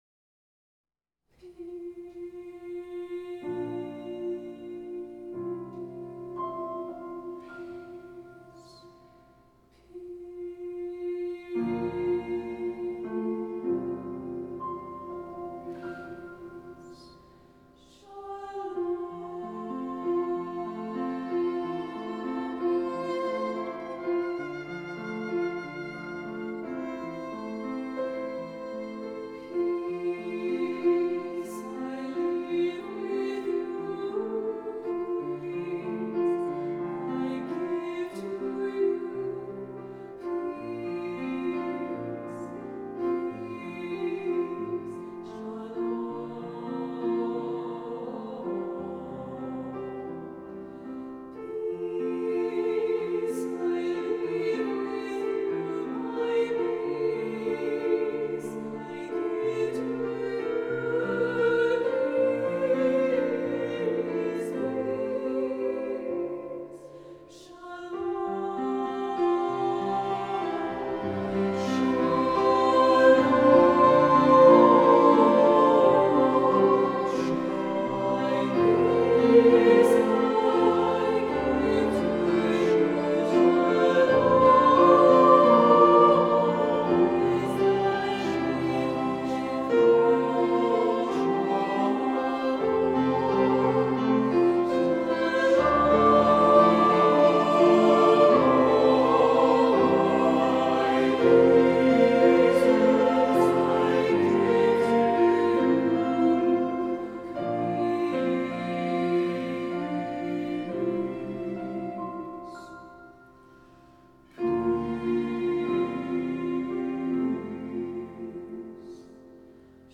choral anthem